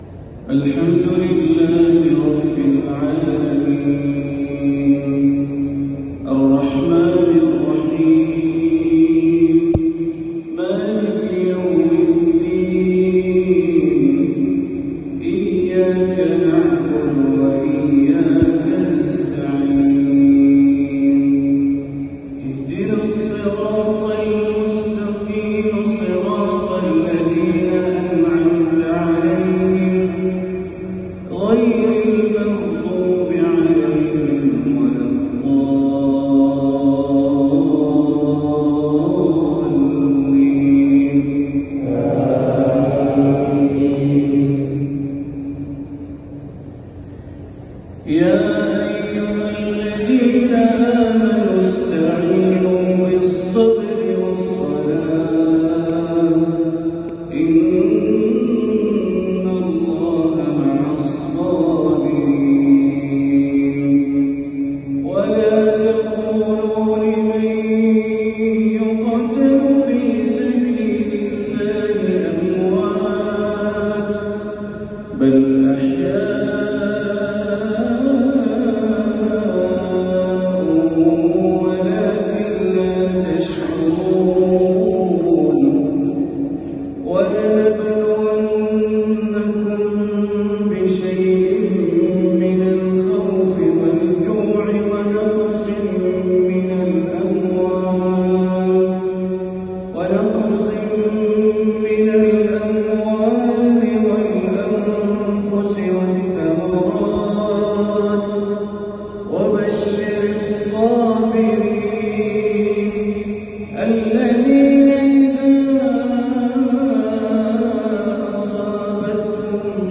تلاوة من سورتي البقرة والفجر | مغرب 7-7-1446هـ > تلاوات بندر بليلة خارج الحرم > المزيد - تلاوات بندر بليلة